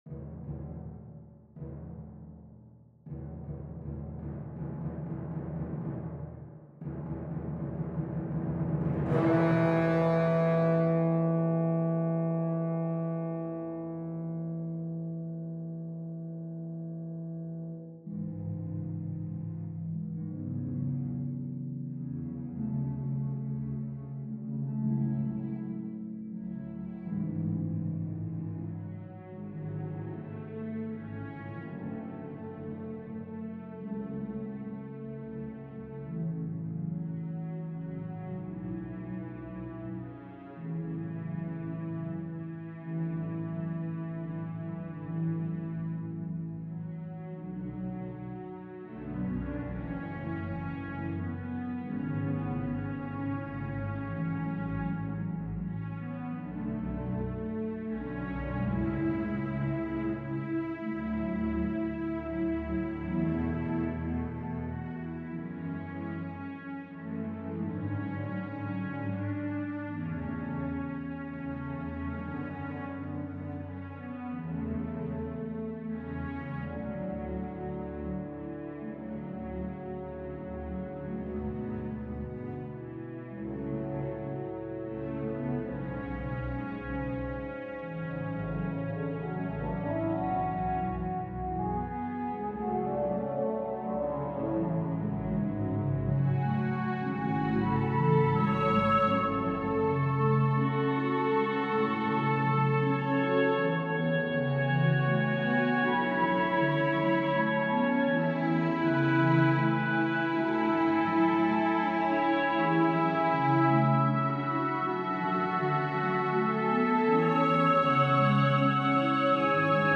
for orchestra
Orchestra: 2,1 222; 4231; timp, 2perc, hp; stgs
The tone of the work ranges from calm to stormy.